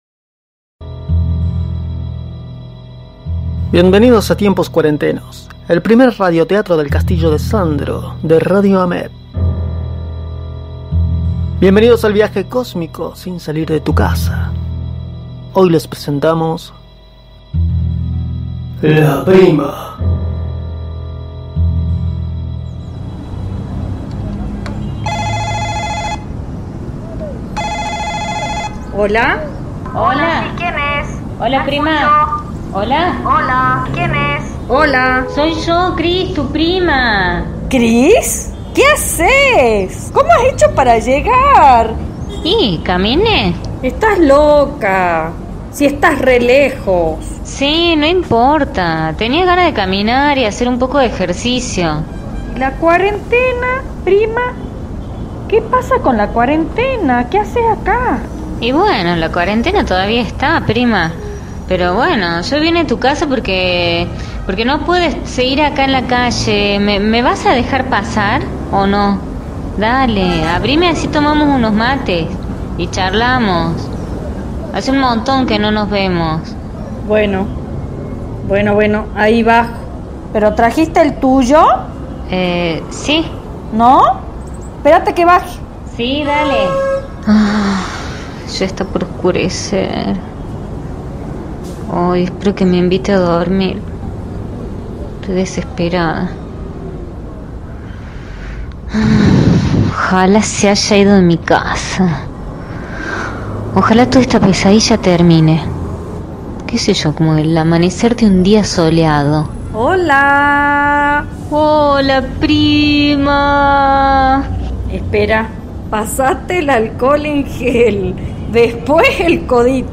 El primer Radio Teatro del Castillo de SANDRO